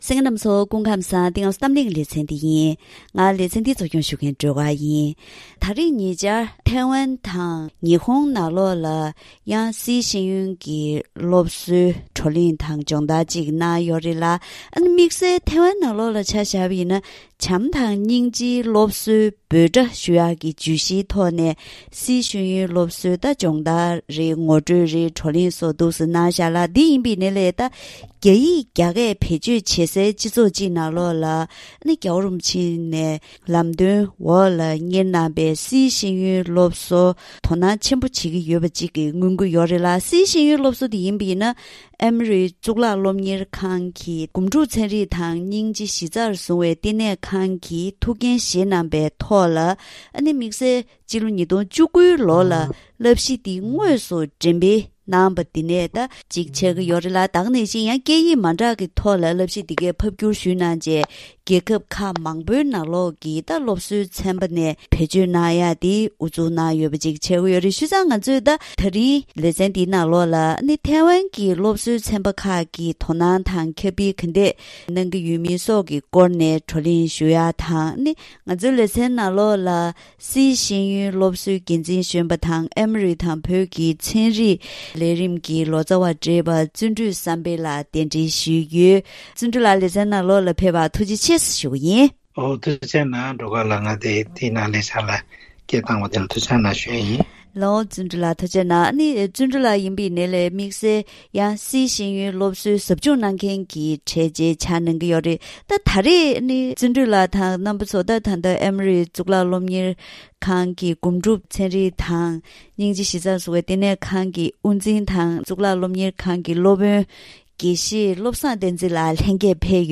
ད་རིང་གི་གཏམ་གླེང་ལེ་ཚན་འདིའི་ནང་འགྲོ་བ་མི་གཅིག་གྱུར་གྱི་འདུ་ཤེས་གོང་མཐོར་གཏོང་ཆེད་དང་བྱམས་བརྩེའི་སློབ་གསོ་དང་འབྲེལ་བའི་སི་ཤེས་ཡོན་སློབ་གསོ་འདི་བཞིན་ཐེ་ཝན་ནང་དོ་སྣང་དང་ཁྱབ་སྤེལ། སི་ཤེས་ཡོན་སློབ་གསོའི་བགྲོ་གླེང་དང་སྦྱོང་བརྡར་སོགས་ཀྱི་སྐོར་ལ་འབྲེལ་ཡོད་དང་ལྷན་དུ་བཀའ་མོལ་ཞུས་པ་ཞིག་གསན་རོགས་གནང་།